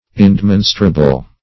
Indemonstrable \In`de*mon"stra*ble\, a. [L. indemonstrabilis.